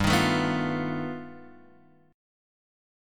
G Augmented 9th